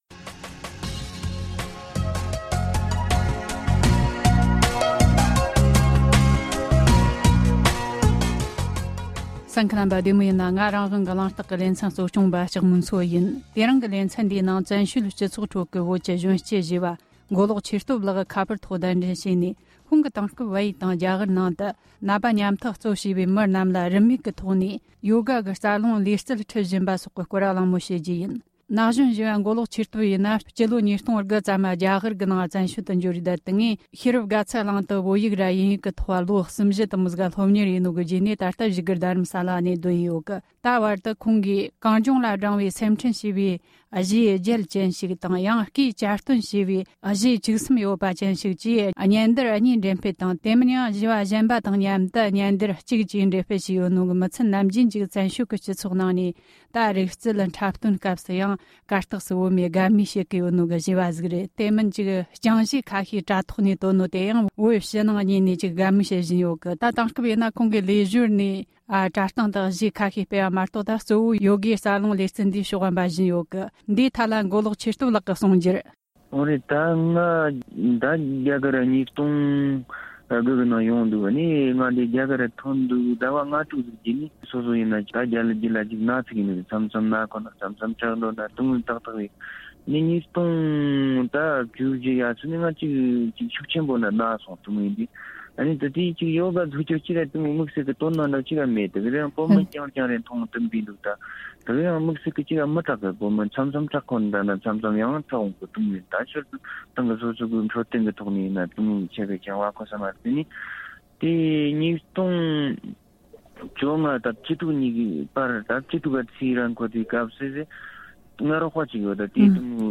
གླེང་མོལ་བྱས་བར་གསན་རོགས་གནོངས།།